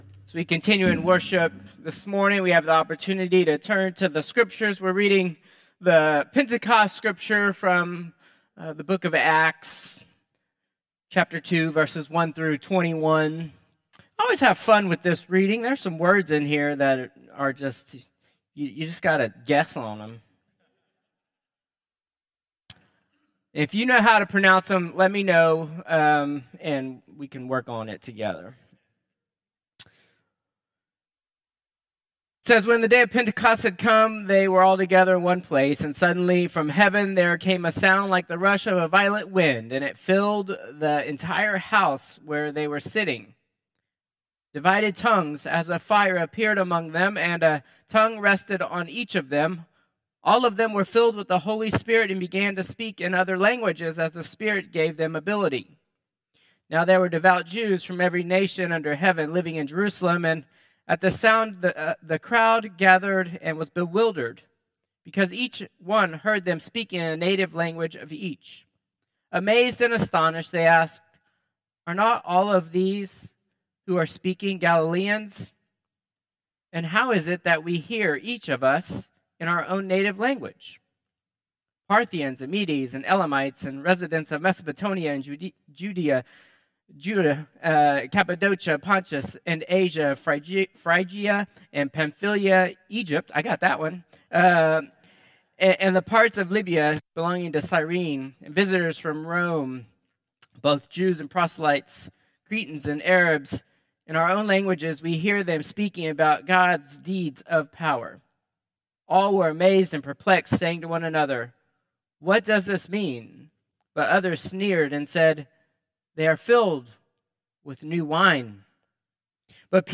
Contemporary Service 6/8/2025